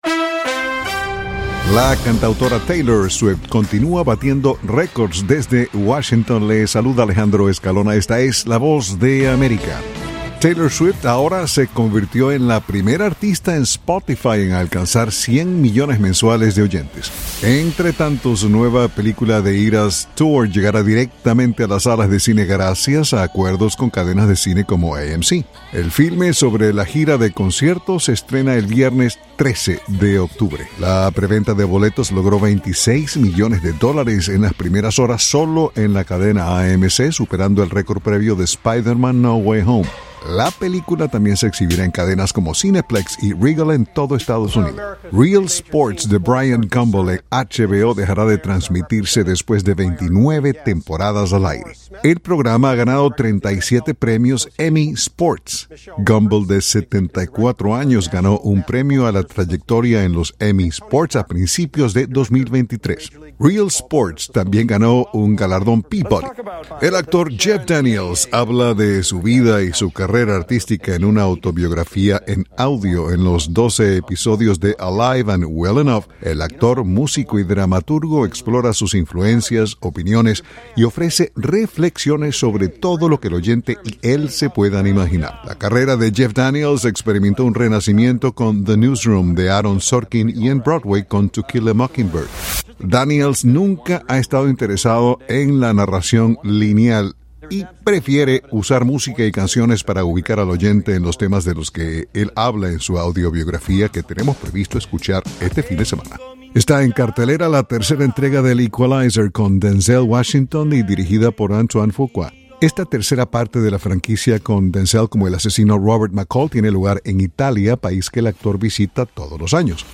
con las noticias del espectáculo por la Voz de América.